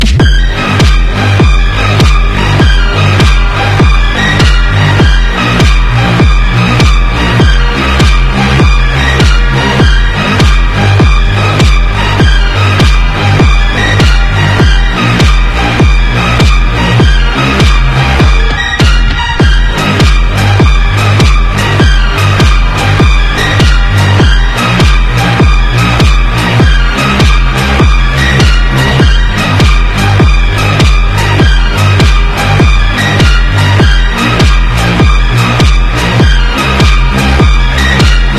Sound Of a Black Hole sound effects free download